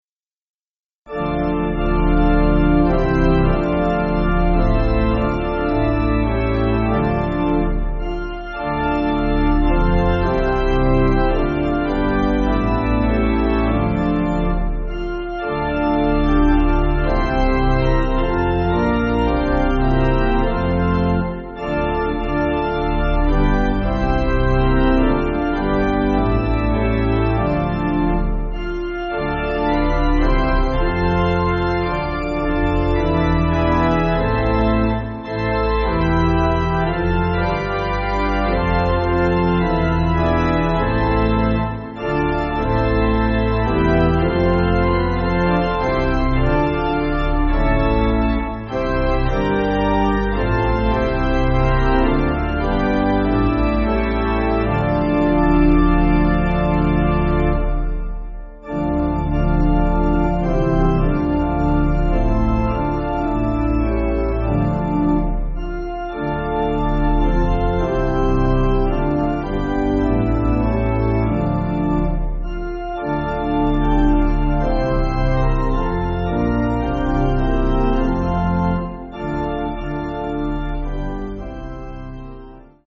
Organ
(CM)   4/Dm